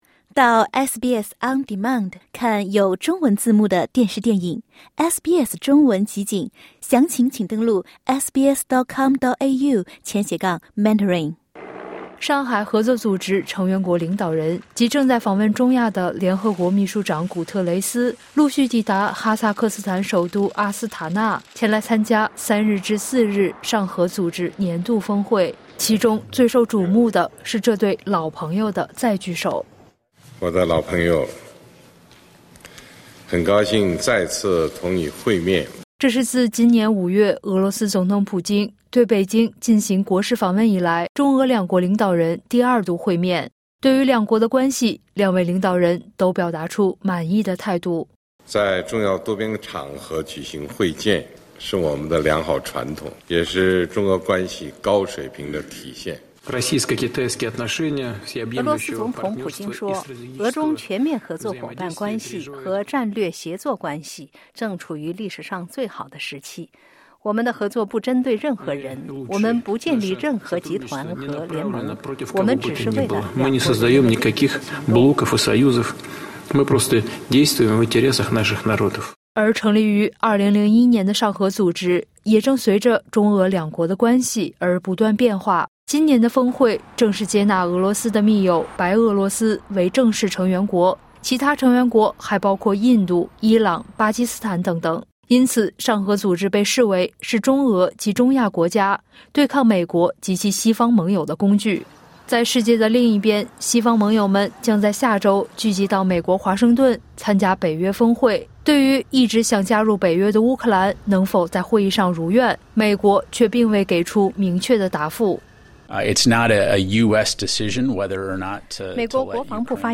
中国国家主席习近平与俄罗斯总统普京的会面是上合峰会的焦点之一。点击音频收听报道